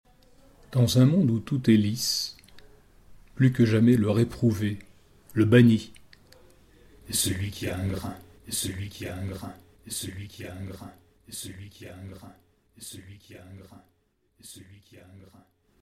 SlowReading*
* SlowReading : lecture aux lèvres, qui ralentit une pensée toujours pressée et galopante